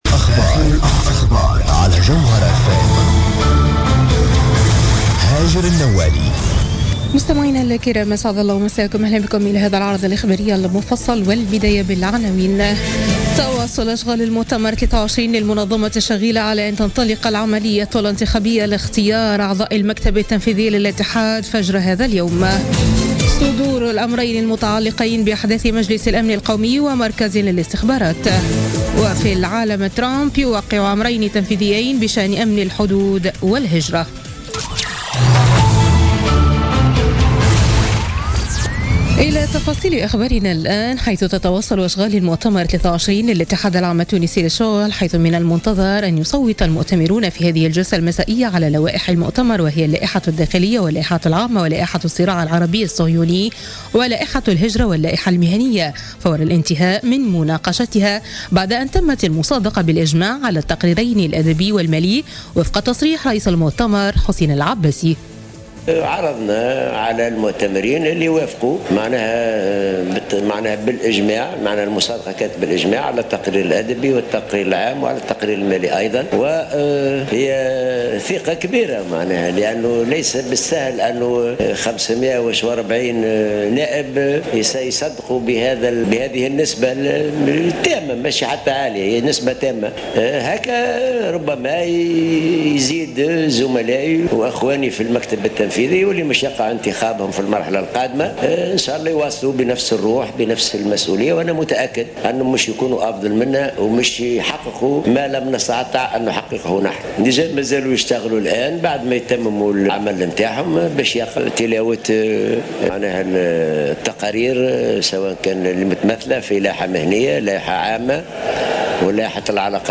نشرة أخبار منتصف الليل ليوم الخميس 26 جانفي 2017